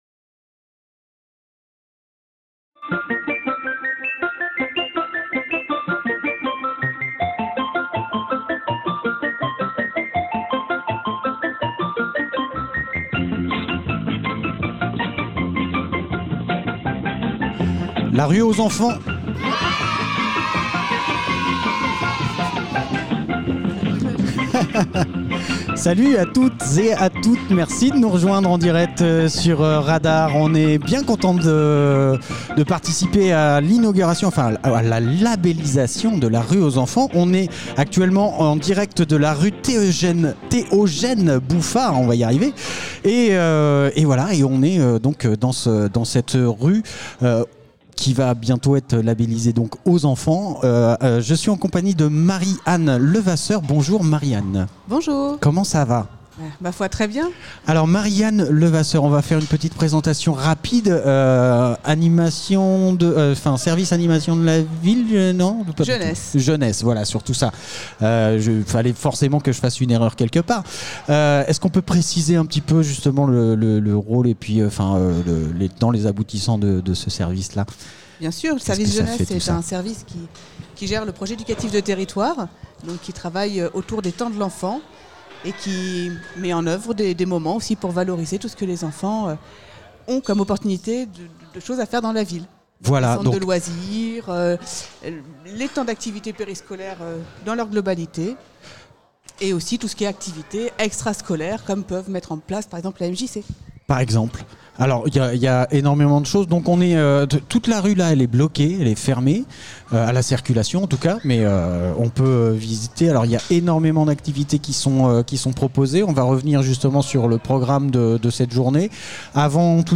La rue aux enfants Interview fécamp associations association rue aux enfants